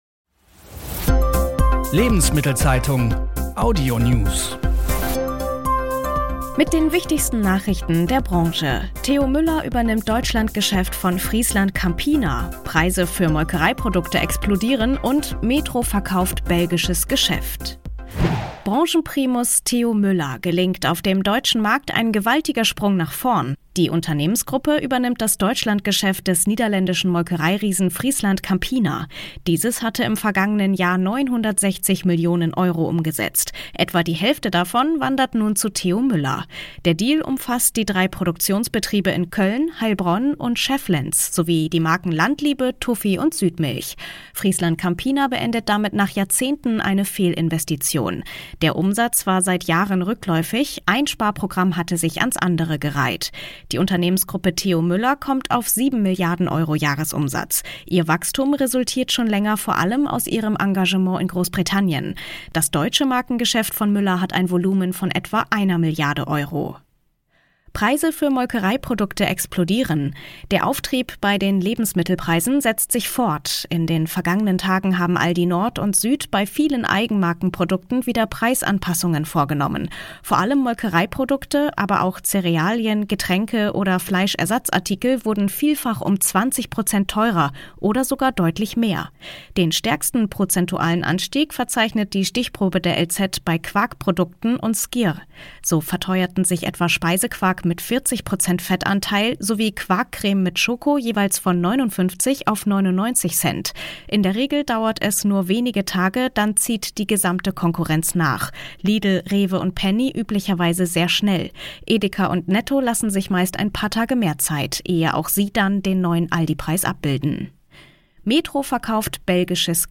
Die wichtigsten Nachrichten aus Handel und Konsumgüterwirtschaft zum Hören